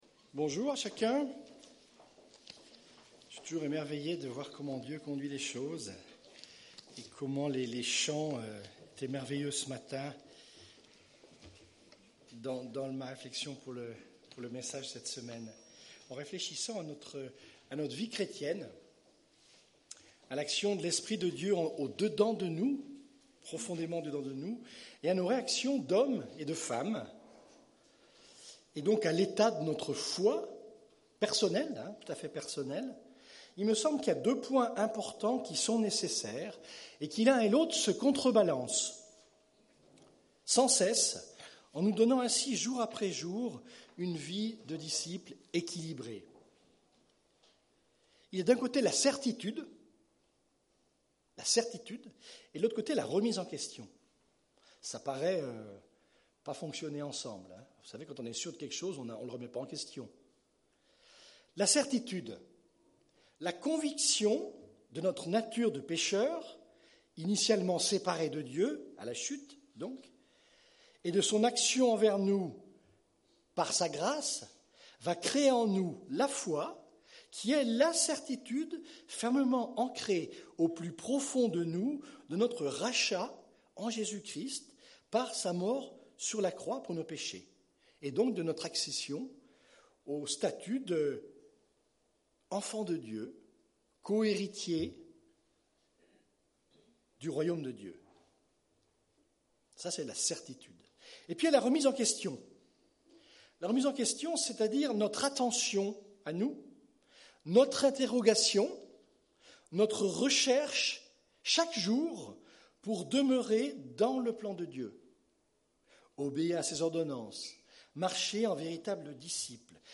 Culte du 27 octobre